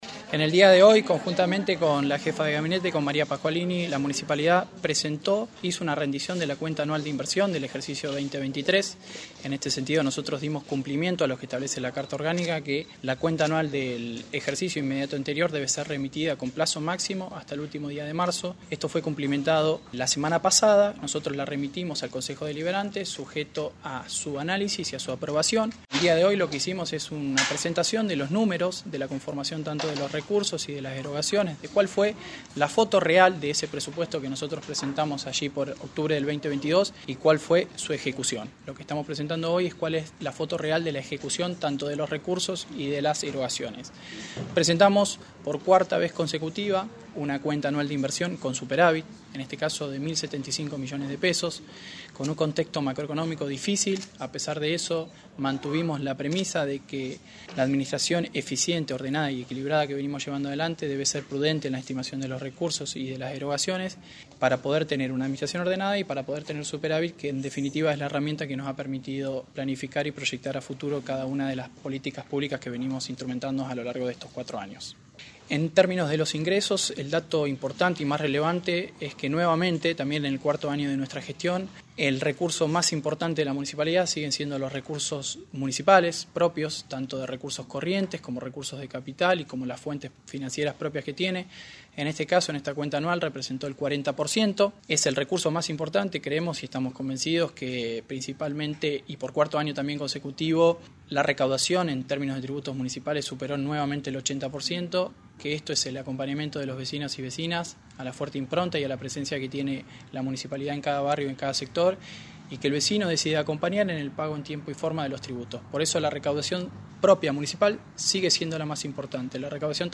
Juan Dutto, subsecretario de Hacienda y Planificación Financiera.